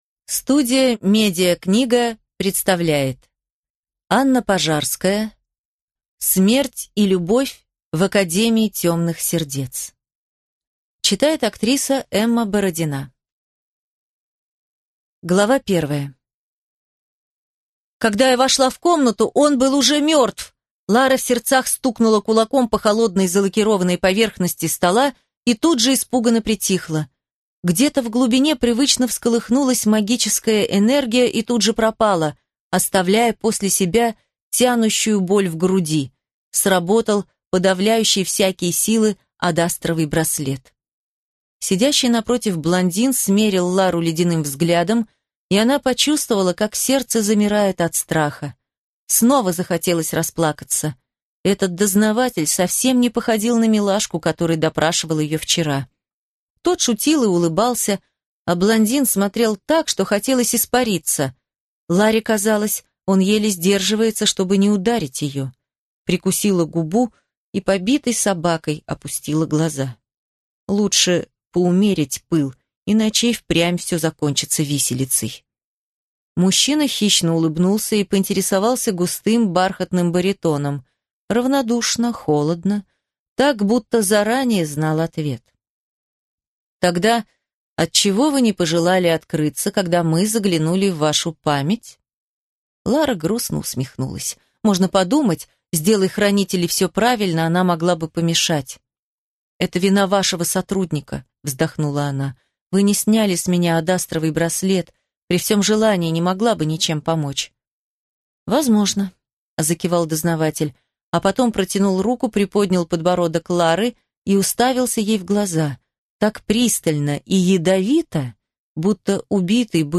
Аудиокнига Смерть и любовь в академии темных сердец | Библиотека аудиокниг